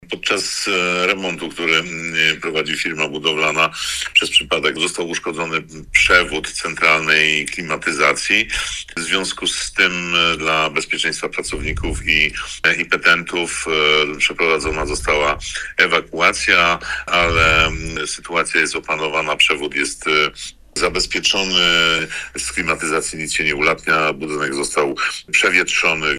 Starosta brzeski Andrzej Potępa uspokaja, że nikomu nic się nie stało, a sama usterka nie stanowi dla nikogo zagrożenia.